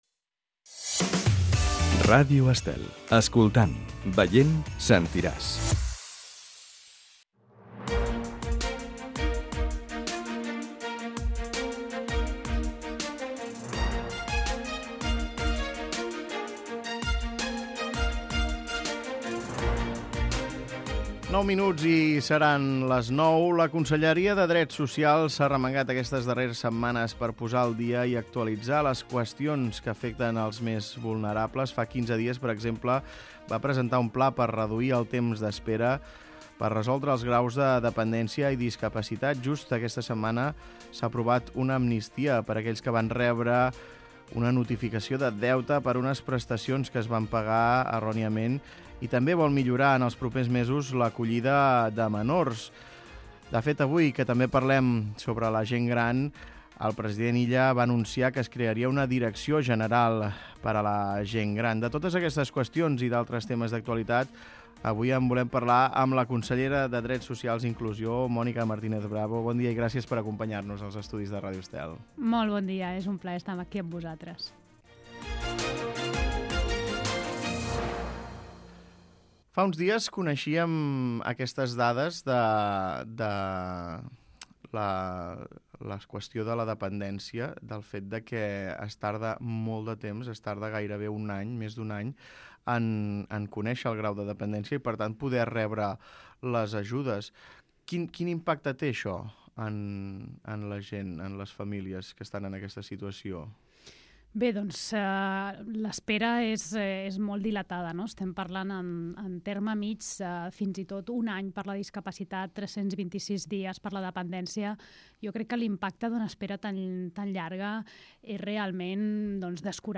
Escolta l'entrevista a la consellera de Drets Social i Inclusióració, Mònica Martínez Bravo